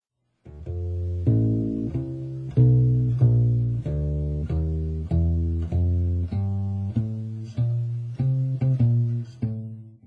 アンプはHartkeの「Kick Back」でエンハンサはオフ、イコライザはフラットに設定し、スピーカからの音を1mほどはなれた所に置いたレコーダで生録。
各弦のバランスも悪くなく、低域もしっかり出ている ピチカートのノイズも比較的小さい。